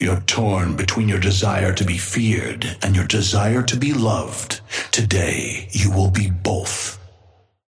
Amber Hand voice line - You're torn between your desire to be feared and your desire to be loved, today you will be both.
Patron_male_ally_wraith_start_01.mp3